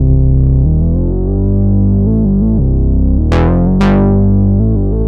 Track 13 - Moog Bass.wav